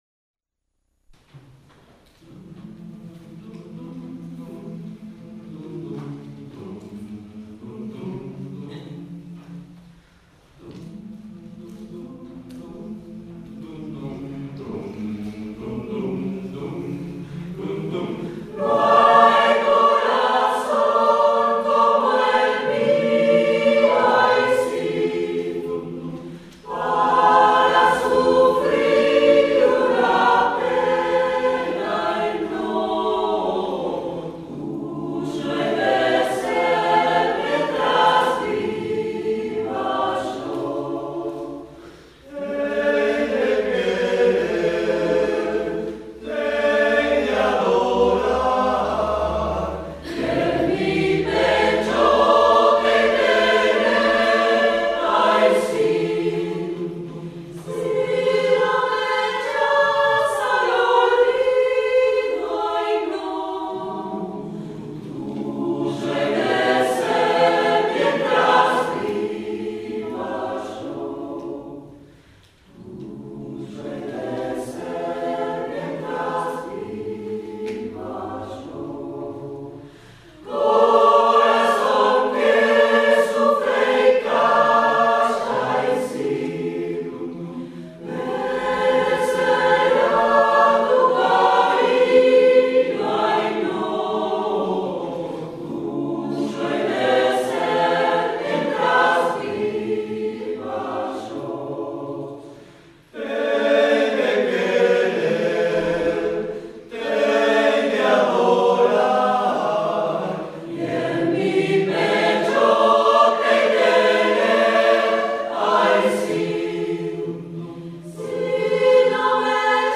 Vidala tradicional